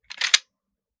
fps_project_1/30-30 Lever Action Rifle - Unloading 003.wav at 362b0a57085fcd4b8174ed5a3358c0ff5455f6fa